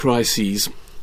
However, the second change, the /s/ turning into a /z/, is nowhere to be seen or heard.
In the example we’re analysing, the speaker makes a pause after the word crises, so he pronounces an /s/.
crises-alone.mp3